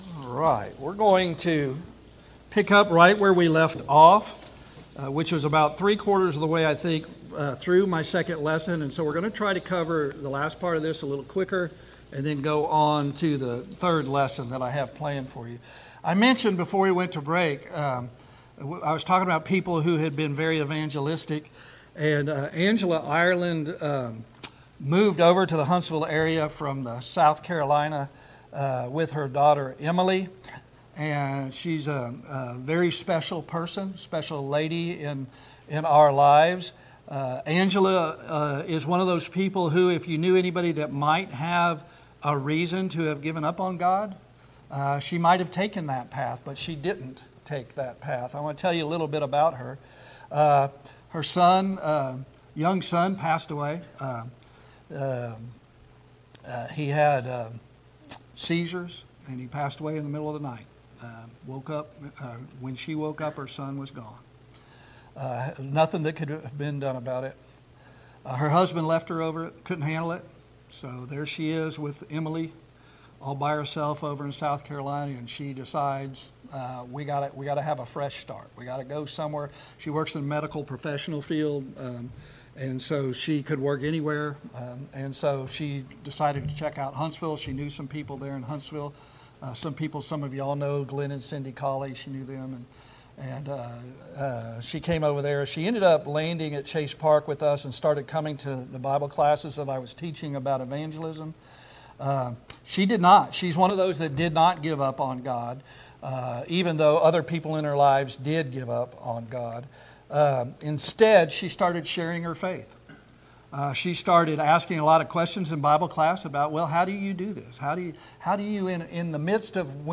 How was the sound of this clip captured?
2026 Spring Gospel Meeting "Think Souls first" Passage: Matthew 5:13-16 Service Type: Gospel Meeting « 2.